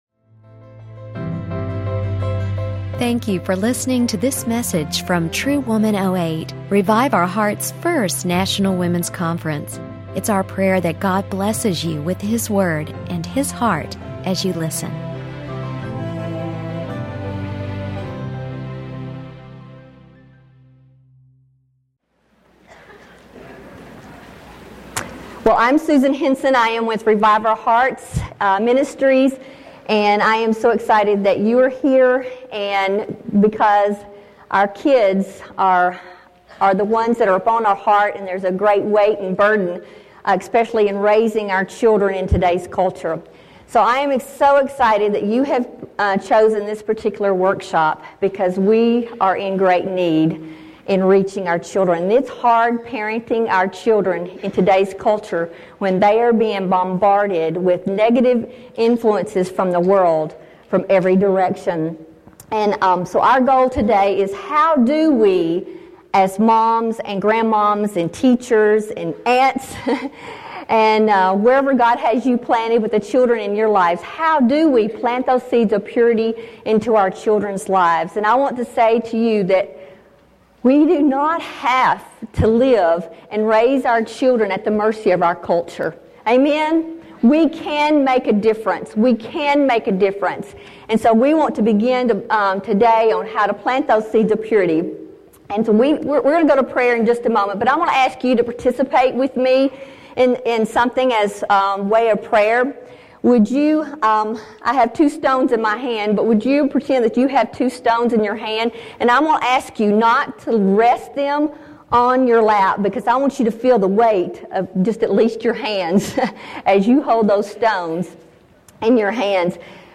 Planting Seeds of Purity | True Woman '08 | Events | Revive Our Hearts